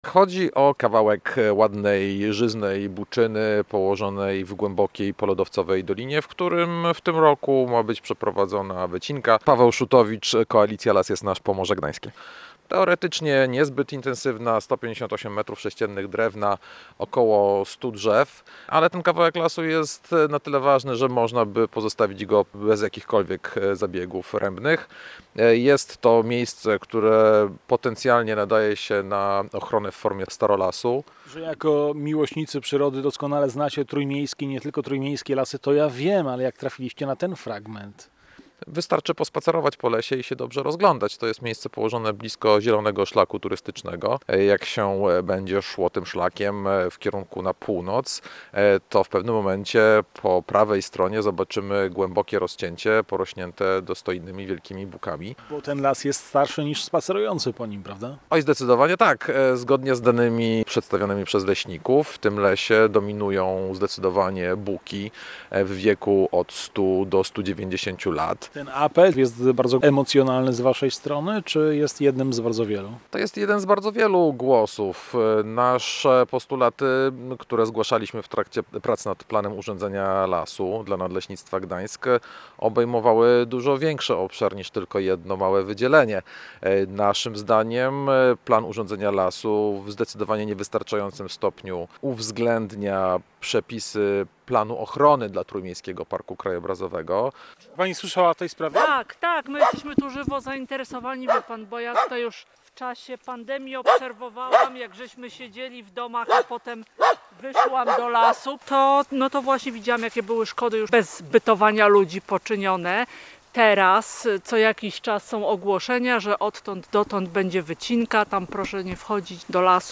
Posłuchaj materiału naszego reportera: https